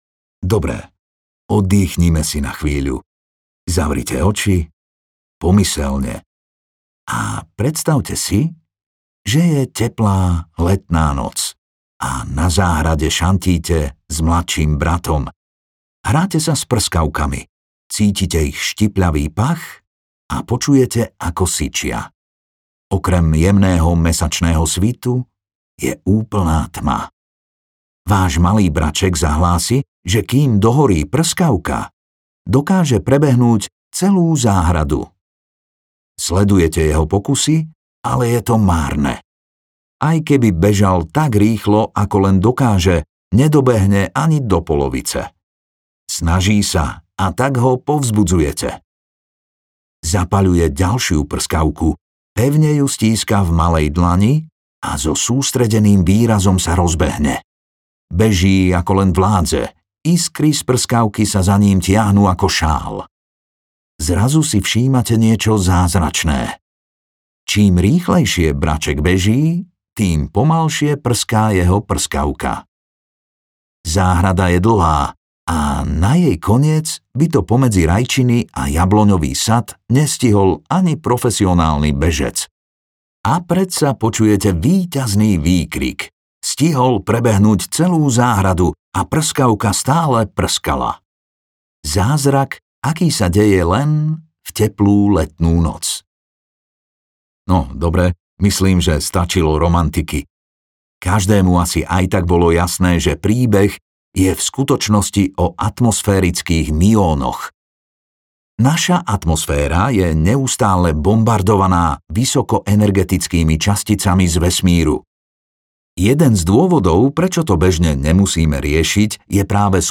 Kúsky reality audiokniha
Ukázka z knihy